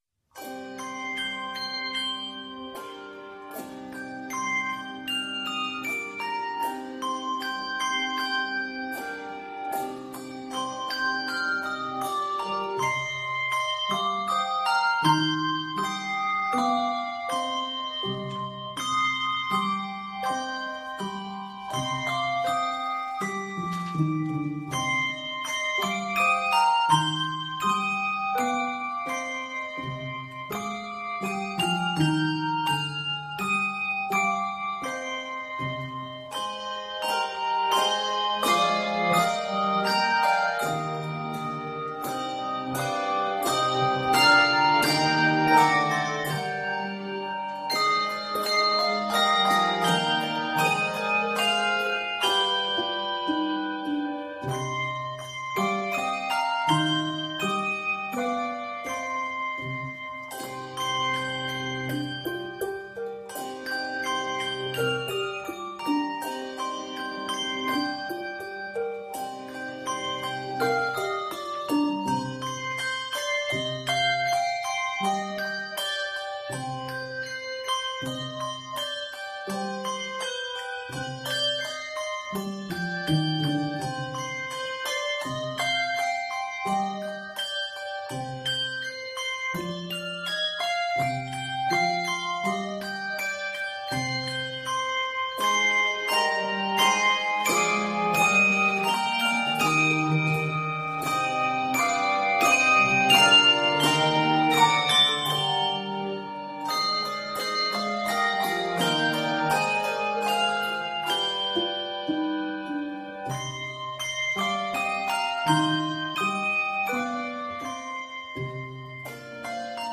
The mystical, minor mood
is punctuated with mallets, tower swings, LV and martellato.
makes a major key appearance toward the end of the piece.
it is set in c minor.